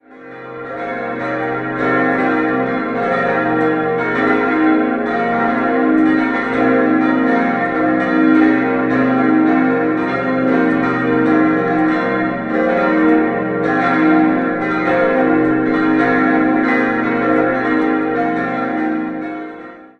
5-stimmiges Geläut: c'-f'-g'-b'-c'' Alle Glocken wurden von Rudolf Perner in Passau gegossen: die große und kleine 1955, Nr. 3 und 2 im Jahr 2013 und die zweitkleinste 2001.